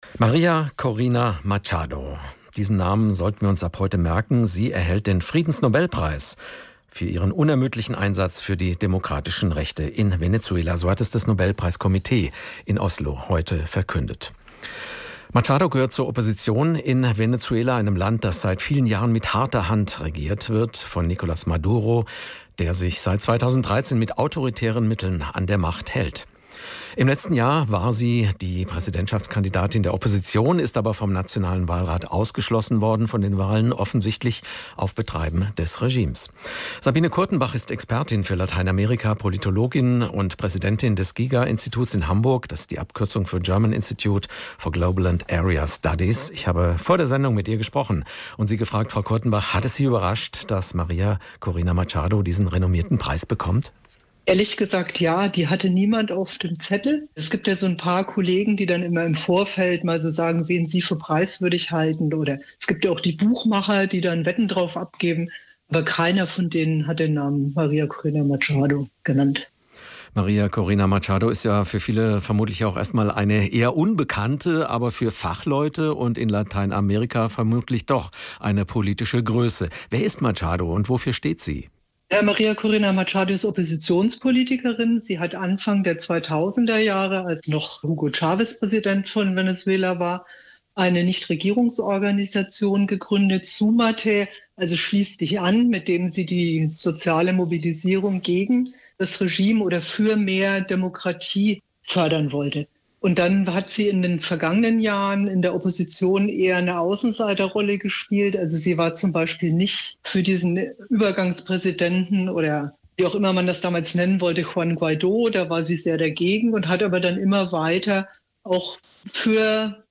hr-iNFO_Interview.mp3